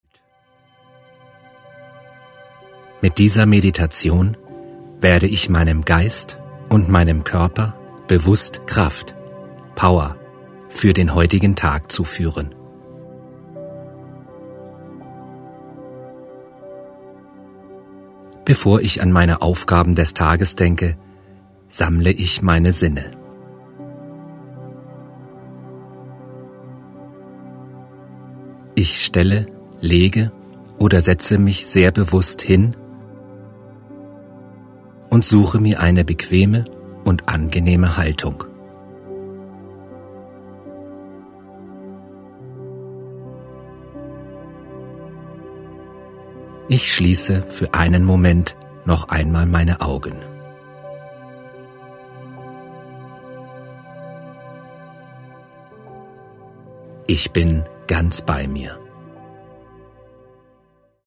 Männliche Stimme   10:28 min
Die begleitende Musik beginnt ruhig und wird nach und nach belebter.